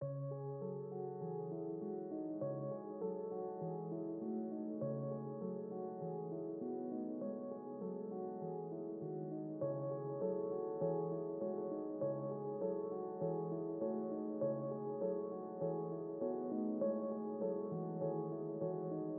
Boominati 100bpm.wav